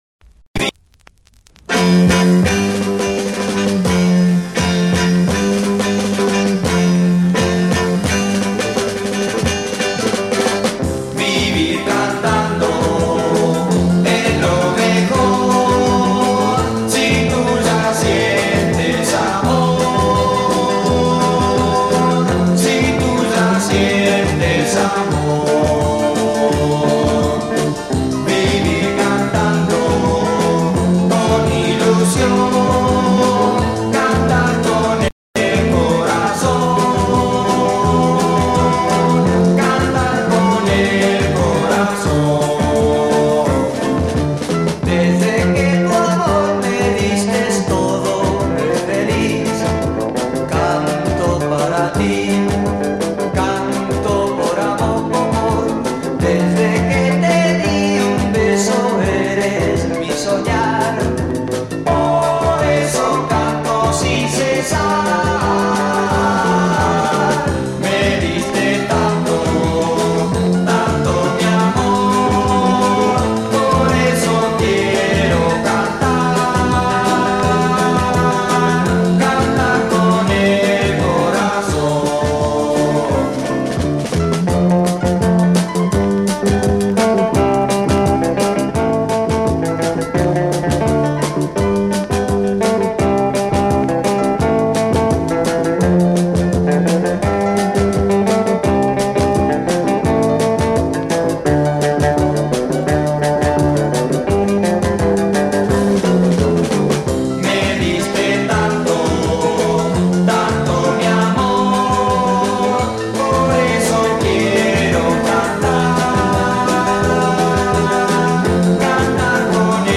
Rare mid-60's Argentinian beat 45